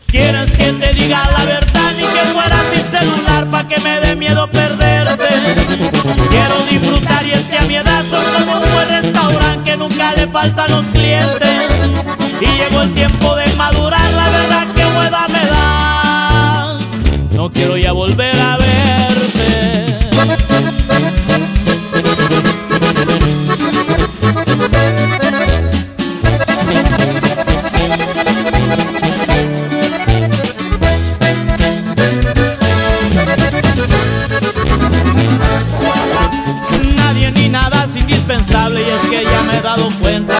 música Pop